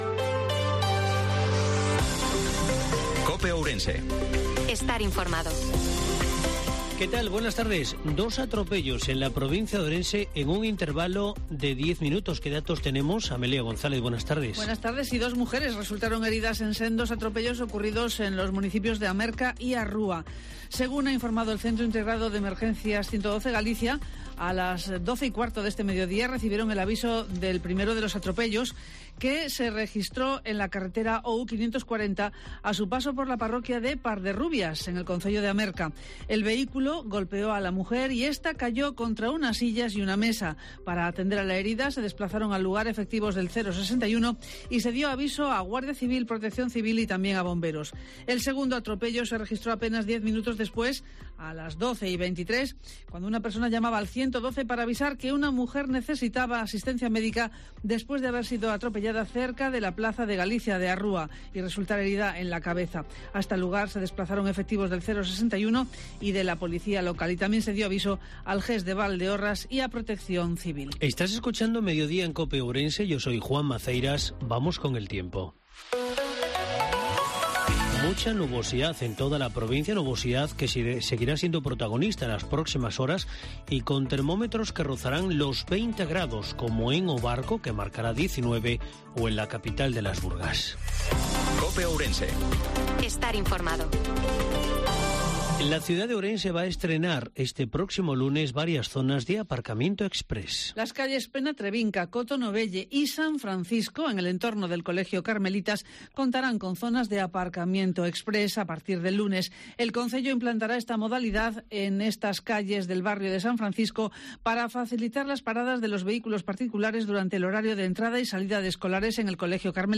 INFORMATIVO MEDIODIA COPE OURENSE-22/03/2023